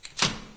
techage_valve.ogg